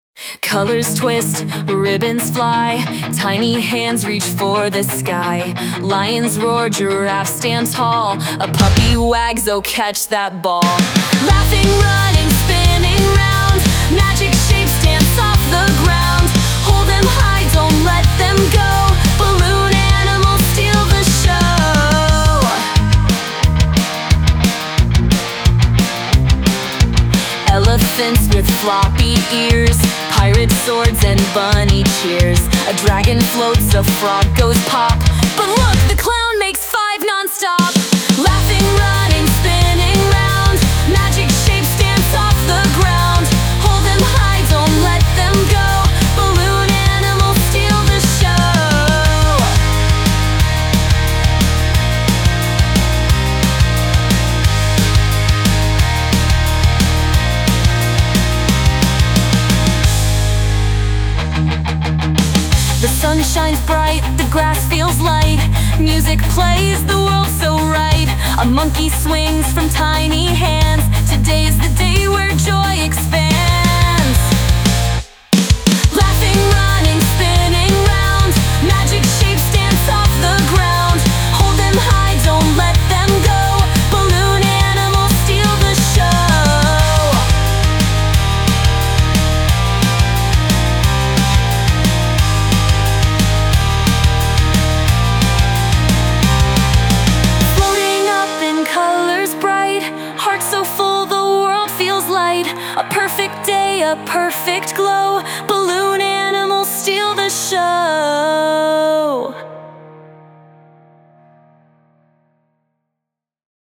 Genre: Children’s Music / Kids Tunes